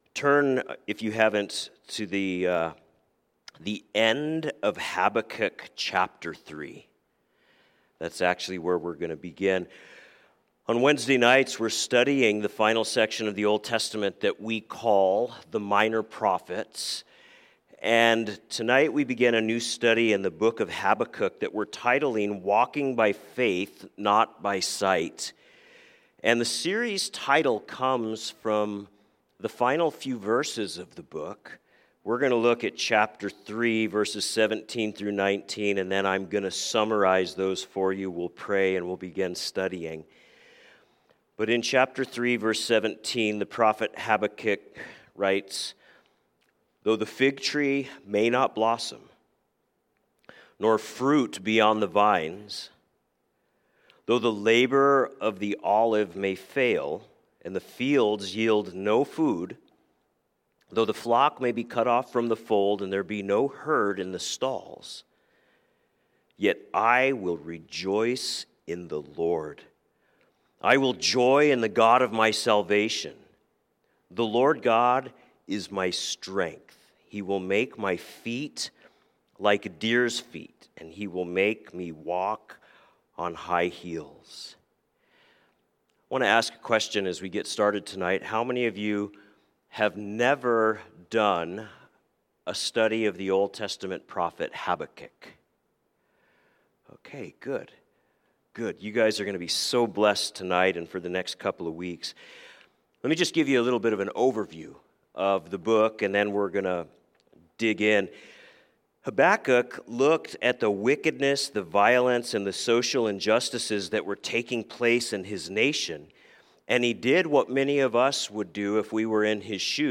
A message from the series "Wednesday Evening."